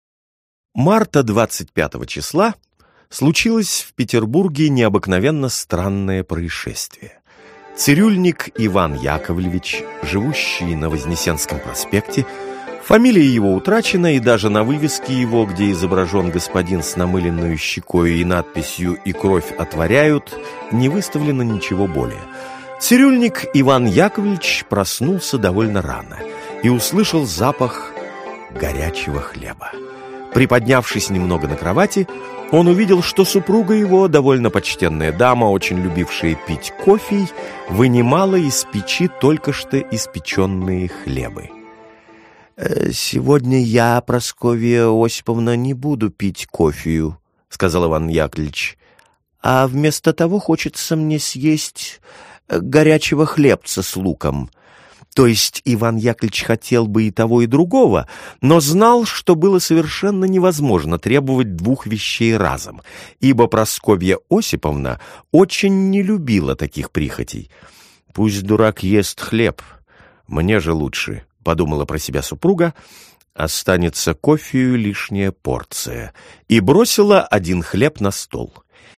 Аудиокнига